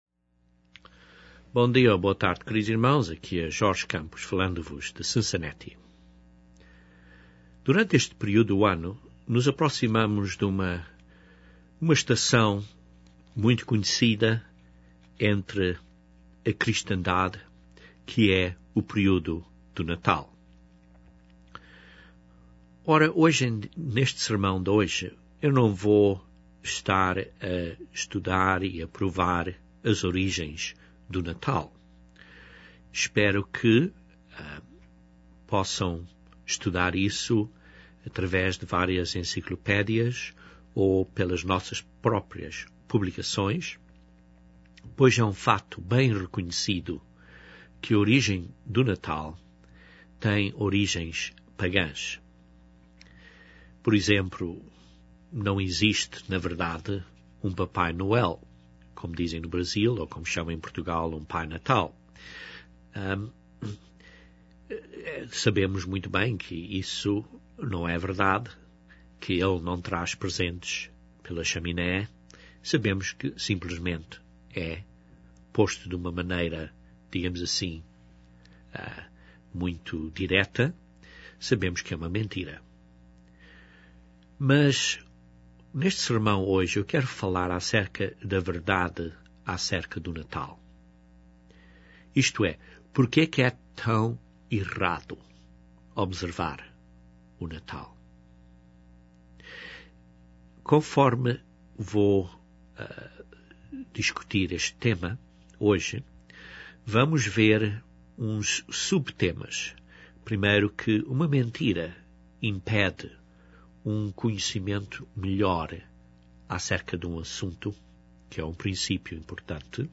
Em consideração destas questões, este sermão analisa este tema da verdade acerca do Natal, e o que